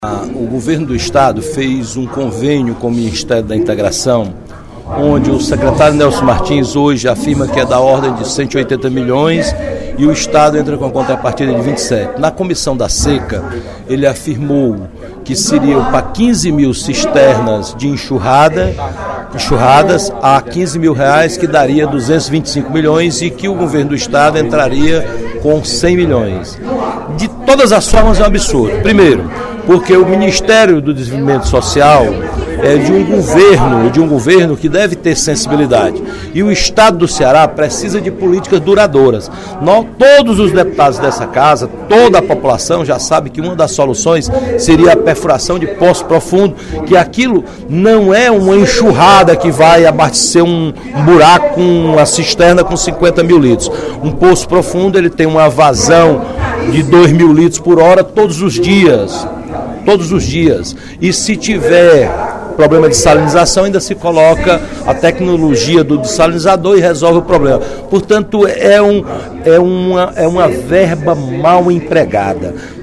Em pronunciamento durante o primeiro expediente da sessão plenária desta quarta-feira (24/04), o deputado Roberto Mesquita (PV) criticou convênio entre o Governo do Estado e o Ministério do Desenvolvimento Social para a construção de 15 cisternas de enxurrada.